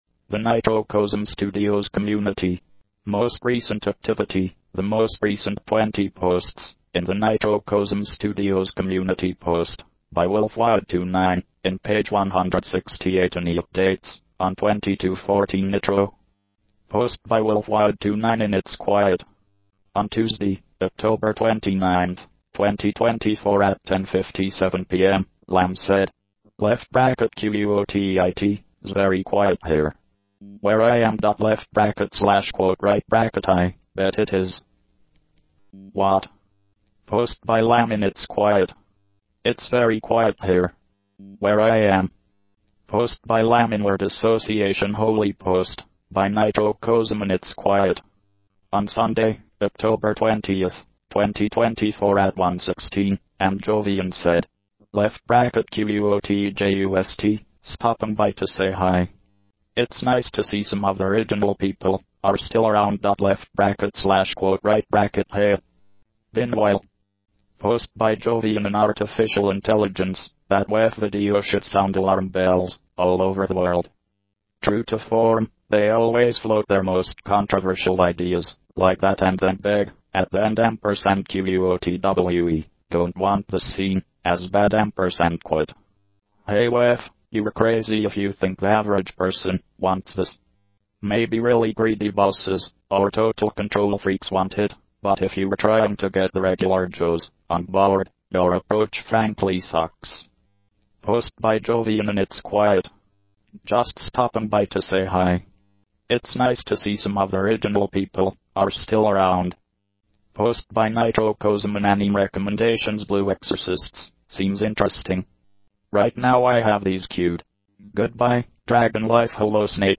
Now, if you want something podcast-like to hear while commuting (and you don't have anything better to which you want to listen), you can listen to an automated text-to-speech recording of the main forums RSS feed!